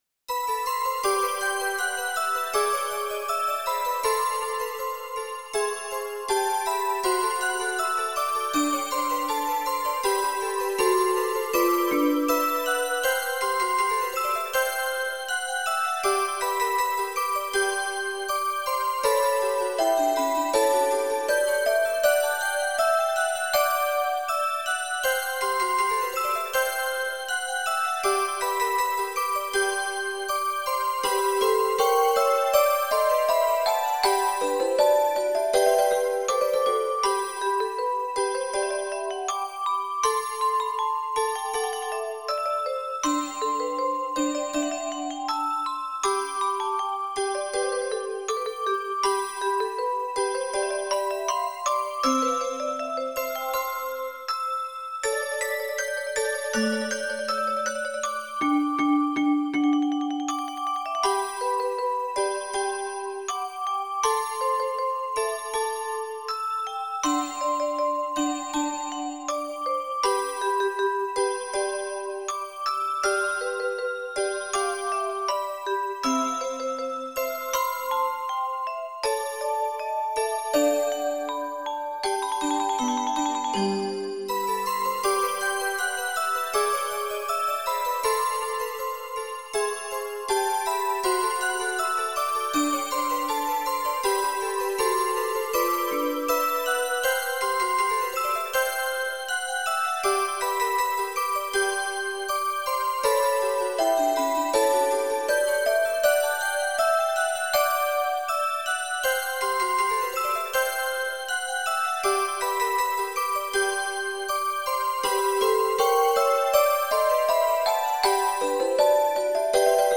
2025/7/5 オルゴール風アレンジです。
そんな感じのヒーリングミュージック風なアレンジです。